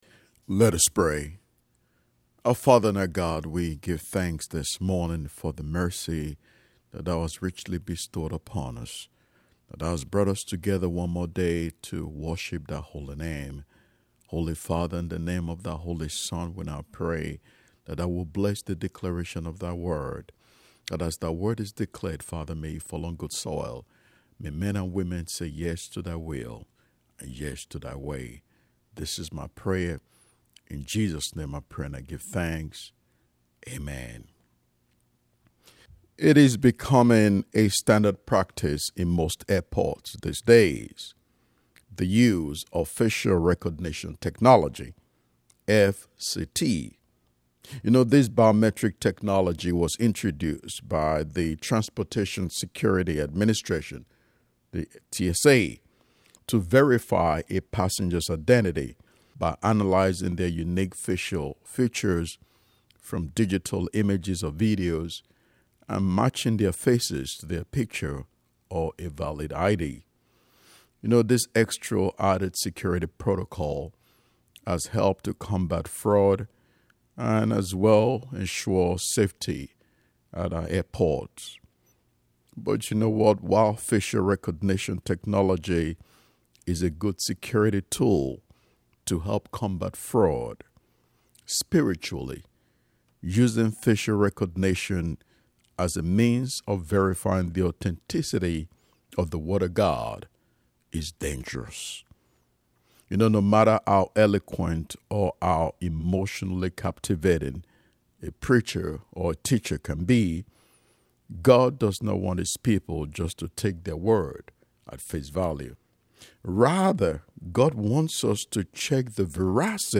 All sermon audio is in mp3 format.